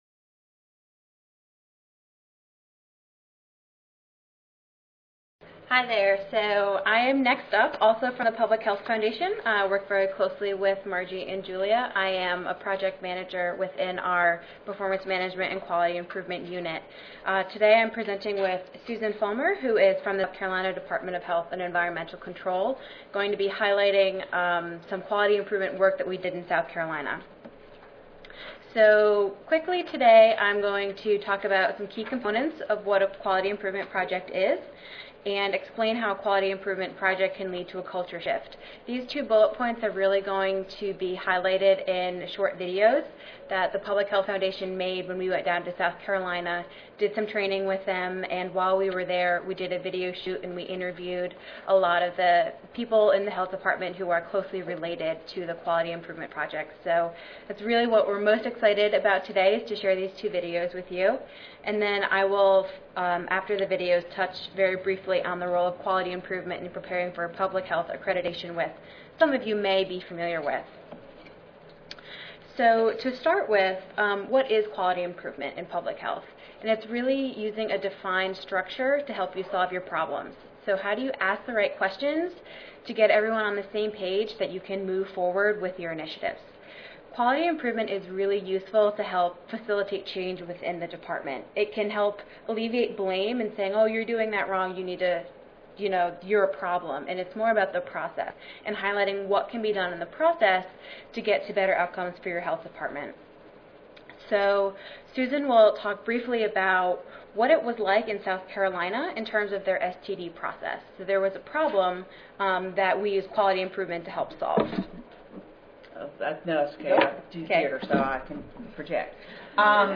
This presentation will cover project goals, results, outcomes, and the role that QI played.